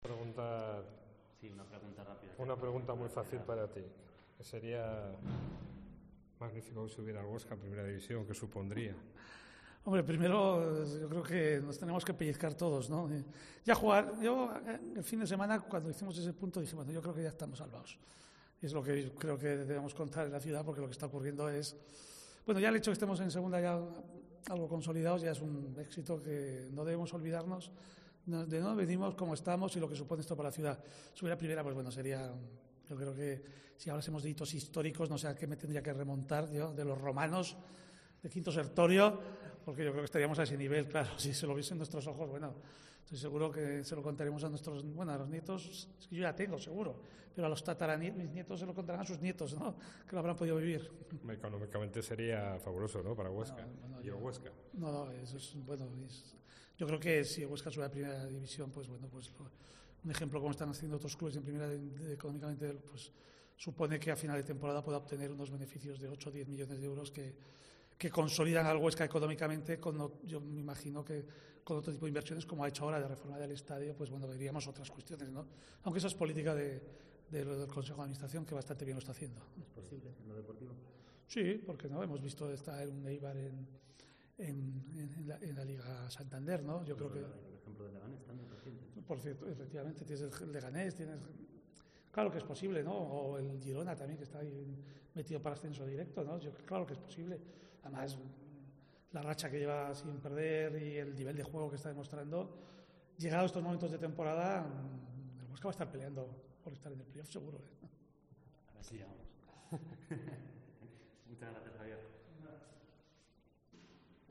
Entrevista a Javier Tebas en Bolea